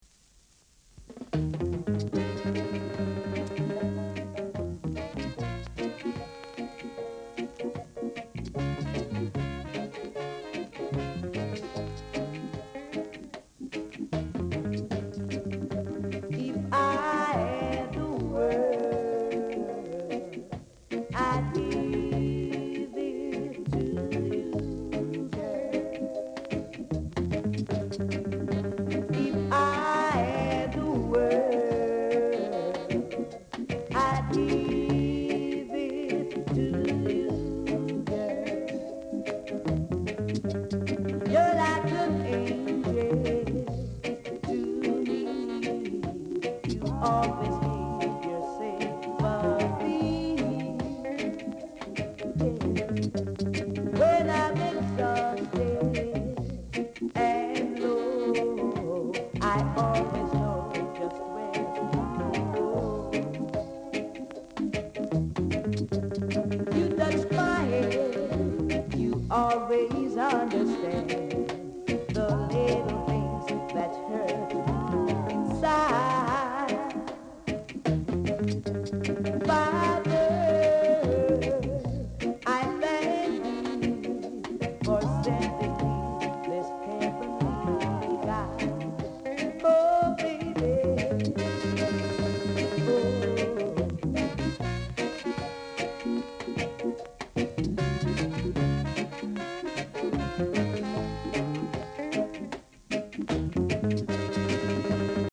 Reggae Male Vocal, Vocal Group
Side1 sample